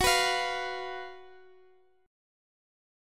Listen to GbM7sus4 strummed